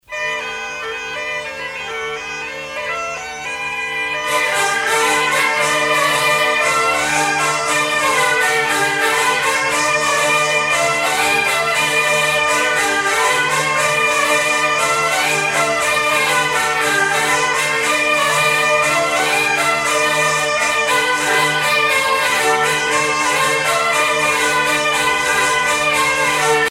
Fonction d'après l'analyste danse : bourree ;
Catégorie Pièce musicale éditée